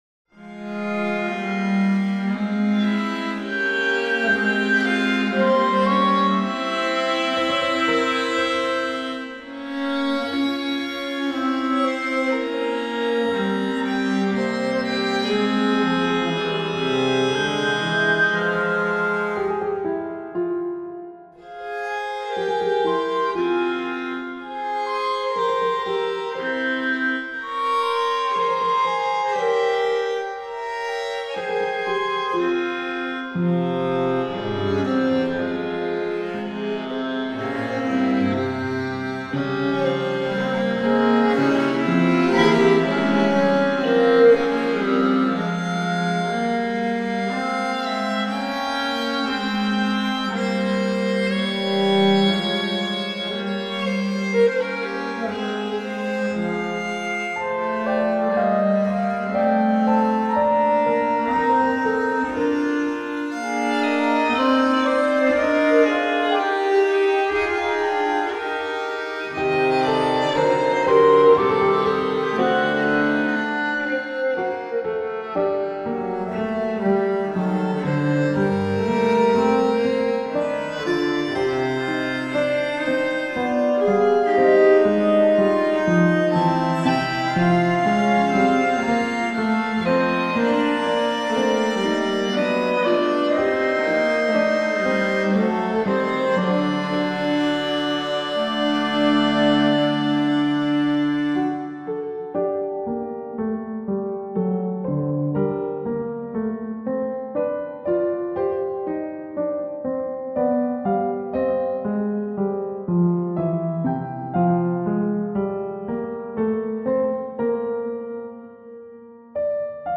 Perform your music with virtual instruments